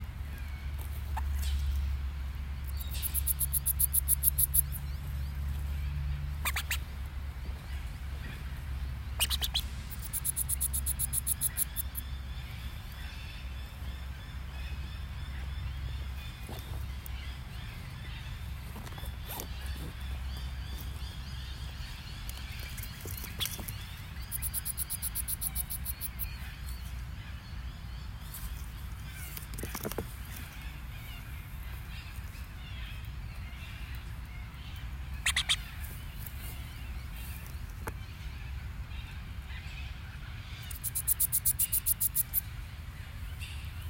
If you do not know what the calls a possum joey makes to its mother sounds like – please click the buttons below to hear the specific species audio recordings.
Ringtail Communication